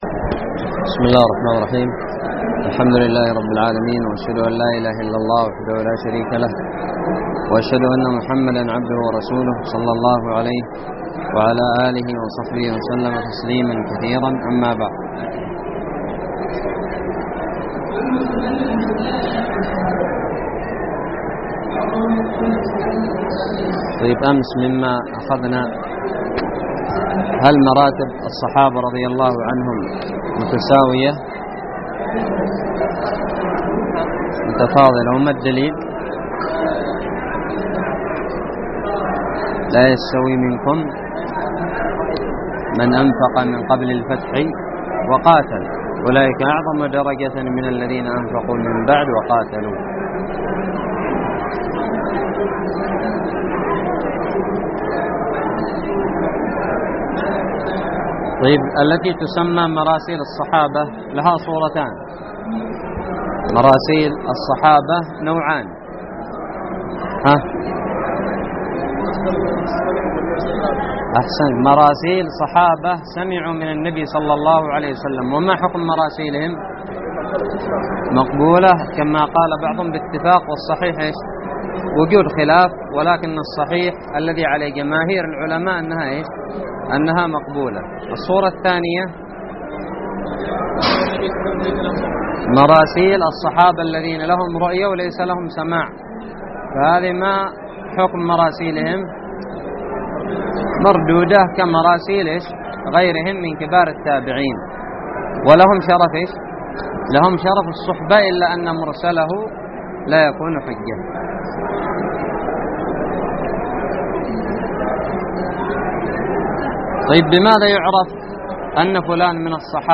الدرس الثامن والثلاثون من شرح كتاب نزهة النظر
ألقيت بدار الحديث السلفية للعلوم الشرعية بالضالع